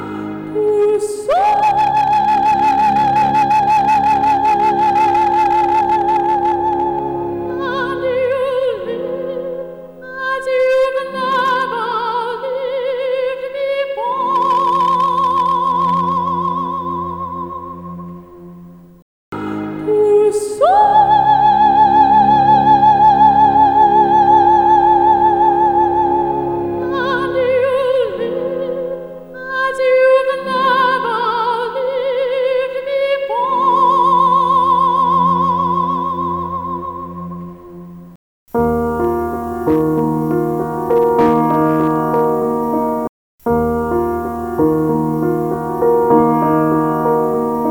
Качество 2 и 3 трека хуже процентов на 10, а это не так кординально Вложения Declipping.mp3 Declipping.mp3 1,1 MB · Просмотры: 371